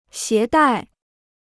携带 (xié dài) Free Chinese Dictionary